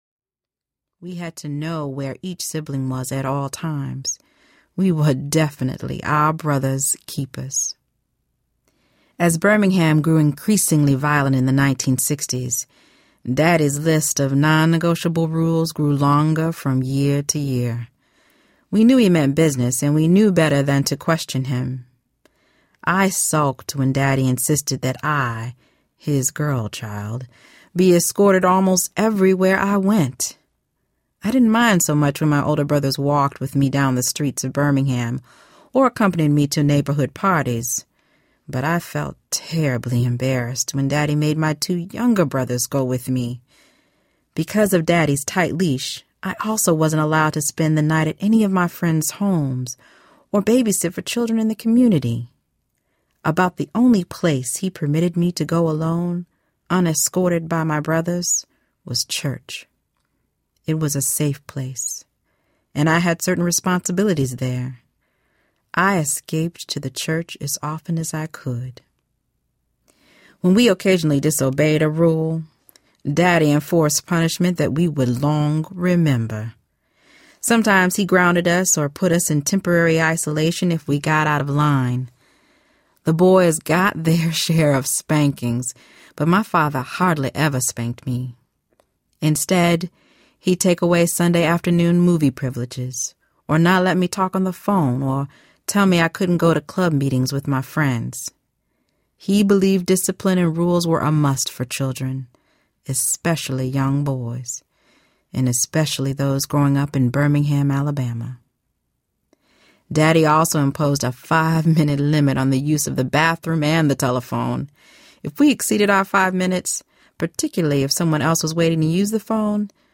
While the World Watched Audiobook
Narrator
7.95 Hrs. – Unabridged